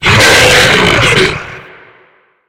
attack_hit_2.ogg